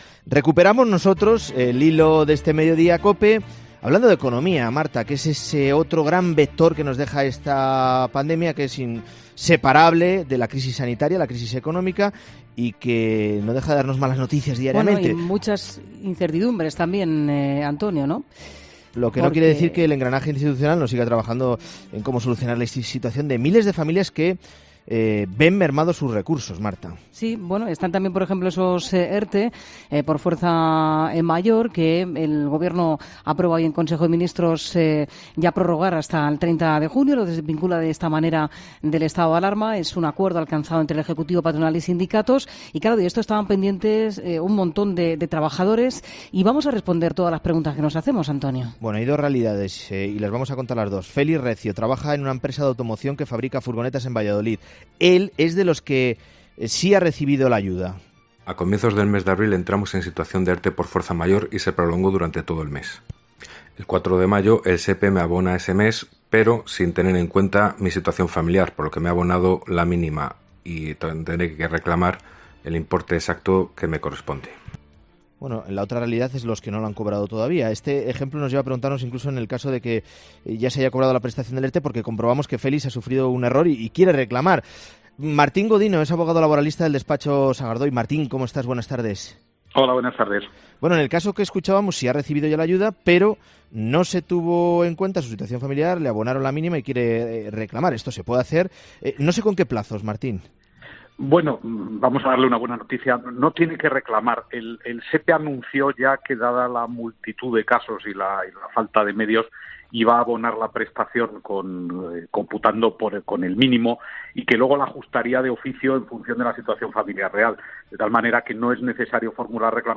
abogado laboralista